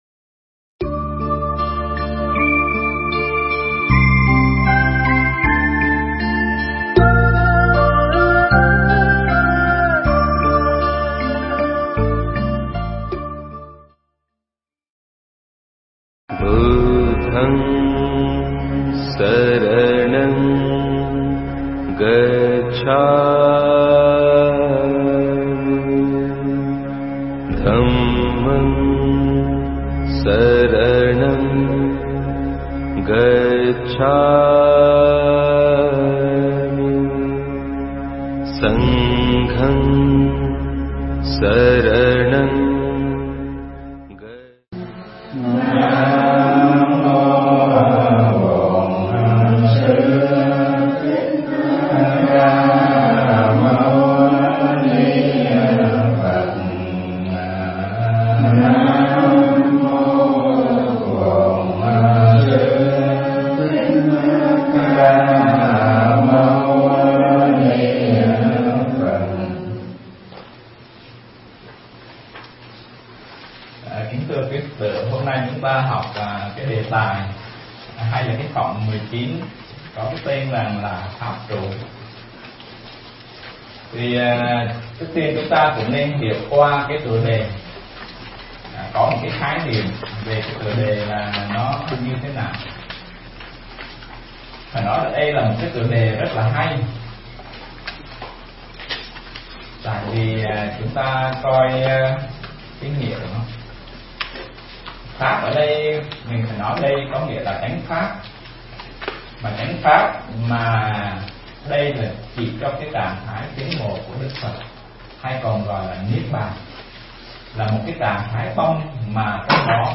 Nghe Mp3 thuyết pháp Kinh Pháp Cú Phẩm Pháp Trụ